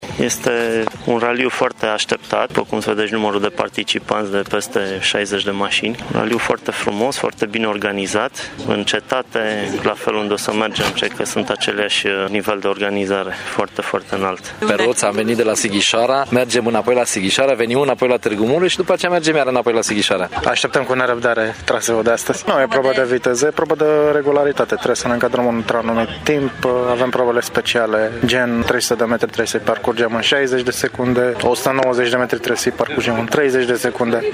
Participanții veniți din toată România și din Europa erau impresionați de nivelul înalt de organizare de la Tîrgu-Mureș: